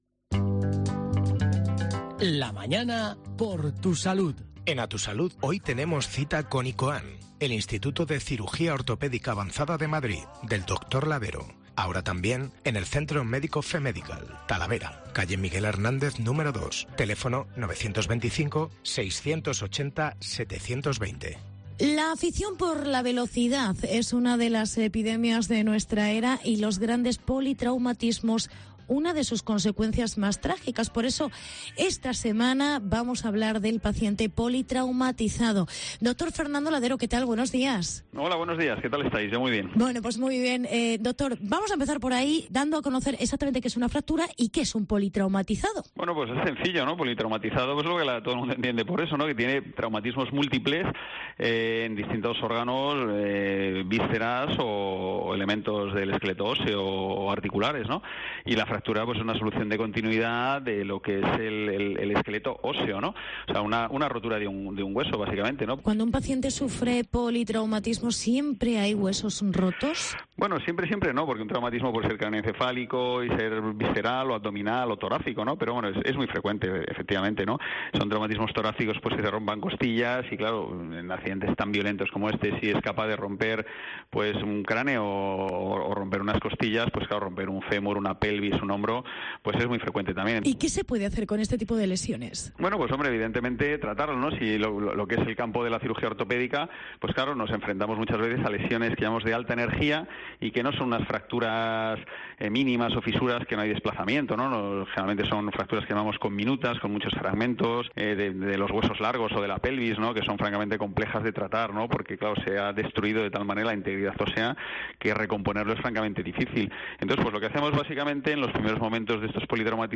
Politraumatismos. Entrevista